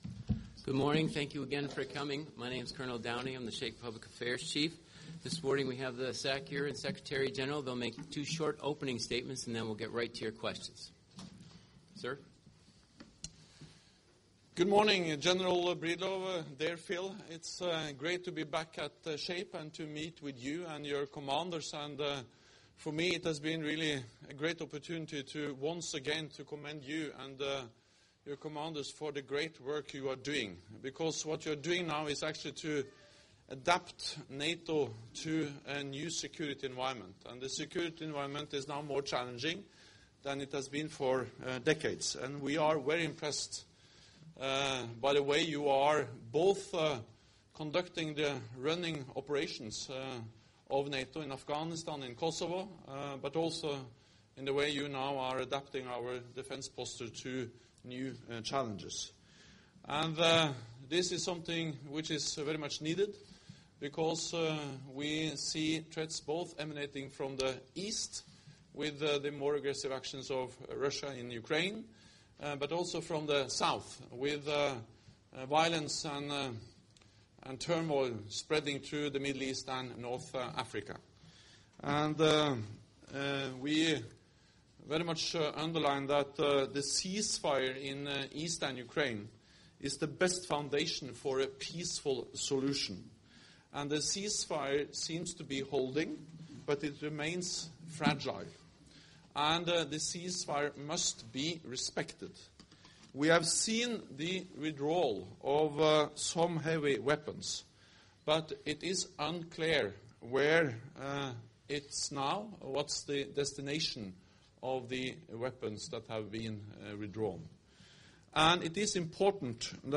Joint press point with NATO Secretary General Jens Stoltenberg and the Supreme Allied Commander Europe, General Phillip Breedlove